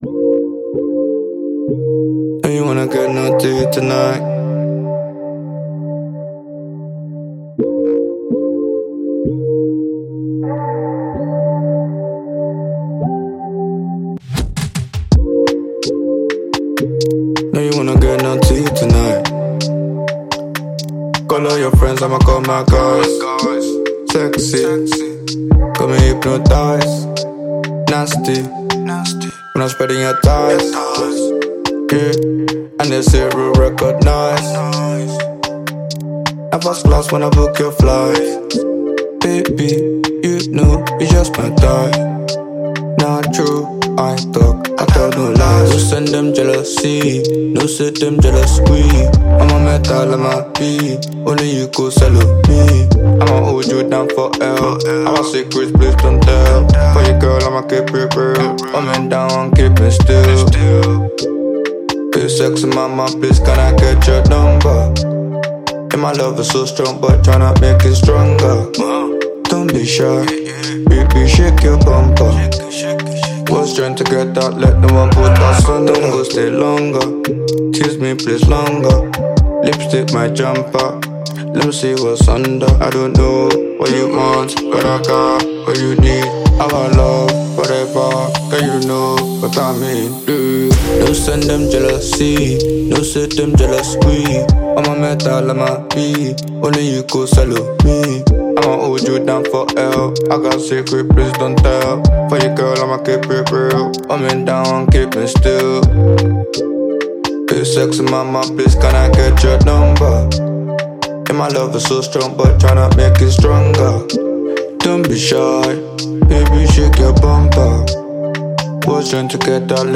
Enjoy this Naija Afrobeat.